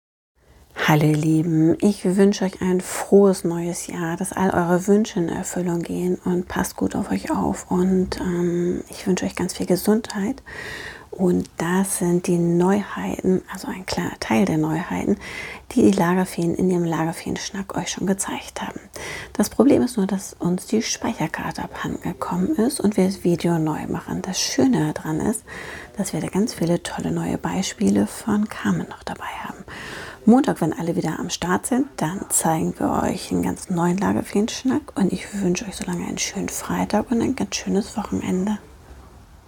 Lachend und informativ erzählen unsere Lagerfeen von Neuerscheinungen